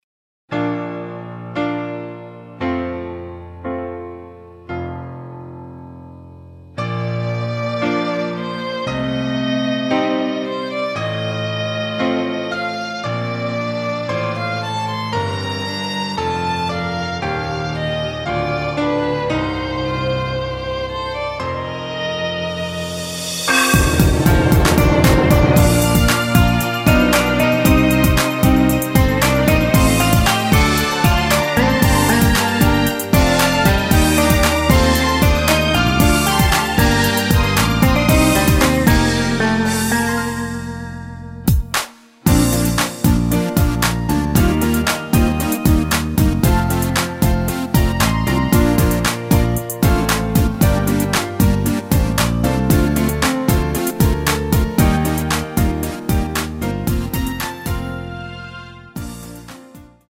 내린 MR입니다. 전주 없이 시작 하는 곡이라 전주 2마디 만들어 놓았습니다.